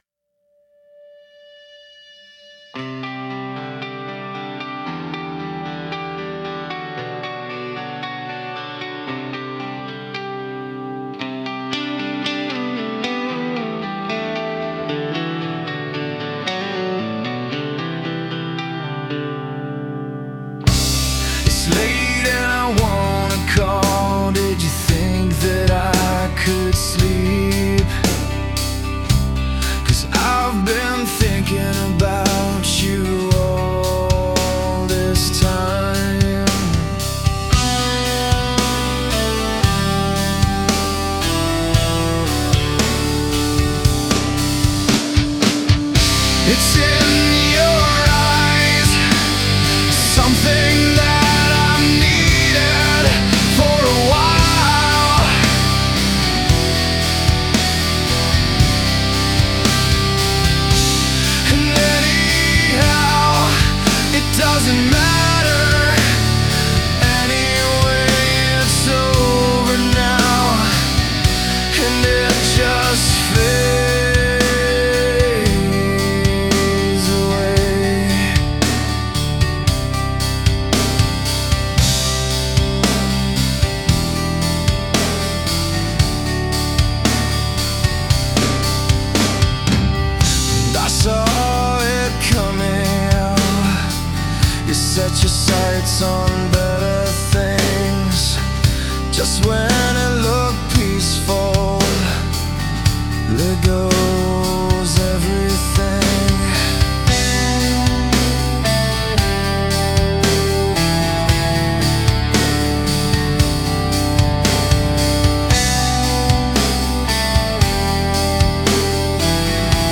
• Estimated BPM: ~72–78 BPM
• Primary genre: Indie rock / indie alternative
• Overall style: Minimalist, emotionally grounded, slow-burn
It sounds human. Slightly imperfect in a good way.